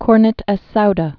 (krnĭt ĕs soudə, -dä)